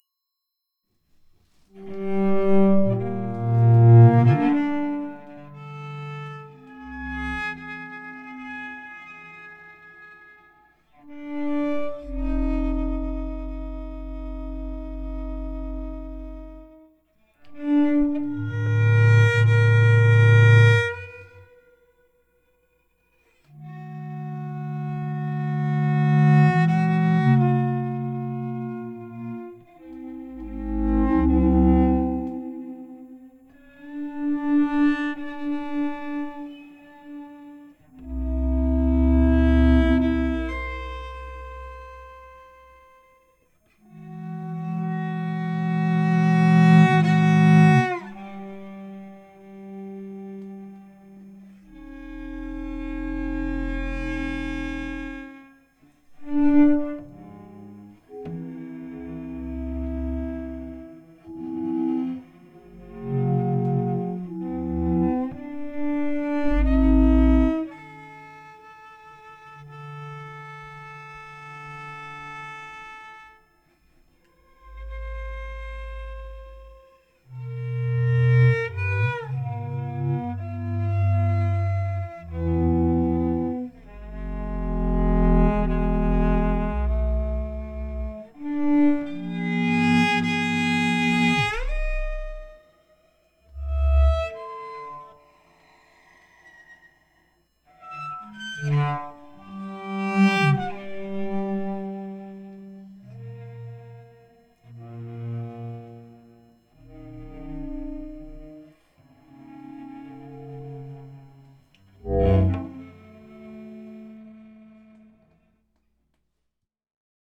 A short piece for cello and analogue ring modulator
(mixed for hifi speakers or headphones, not laptops!)